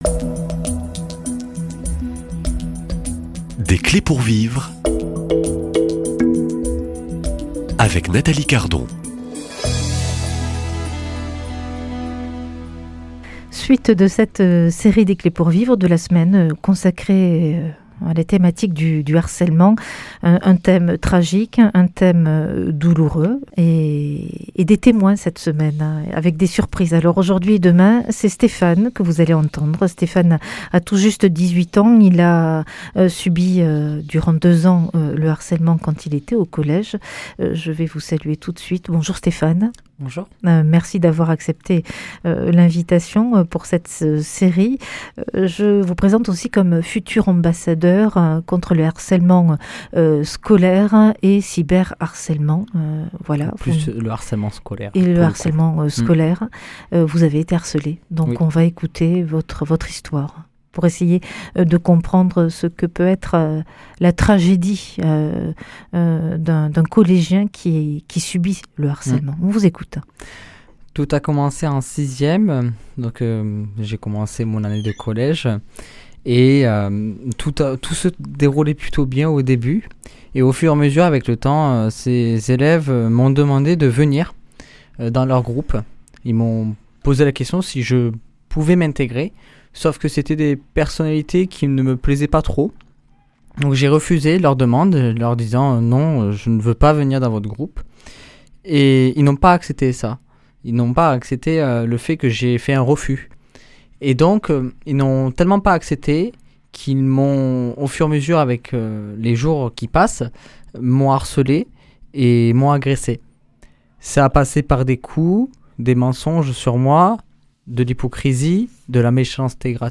[ Rediffusion ] Le harcèlement détruit des vies
Témoignages \ Des clés pour vivre